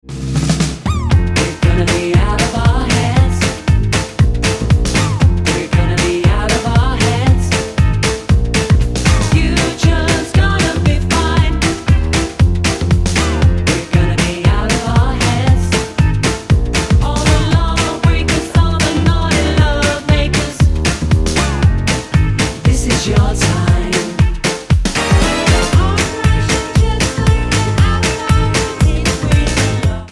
Tonart:Fm mit Chor